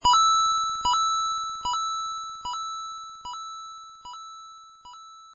smb_coin_ninjini.mp3